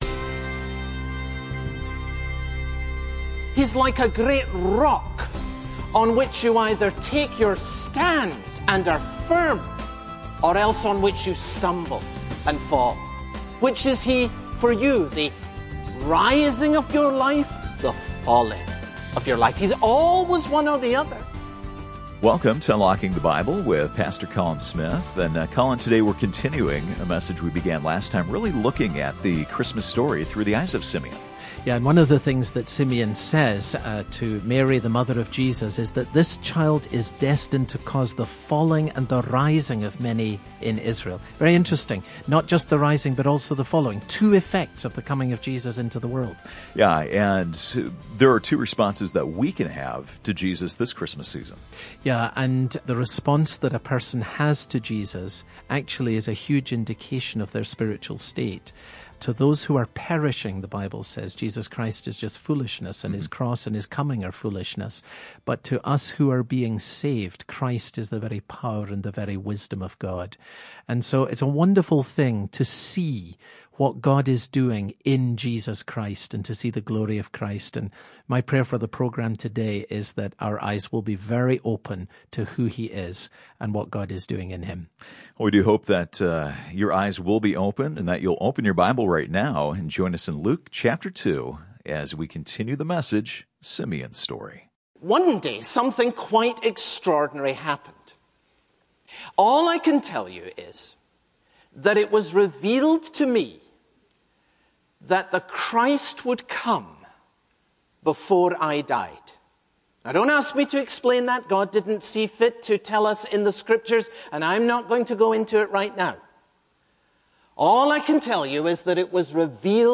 Broadcast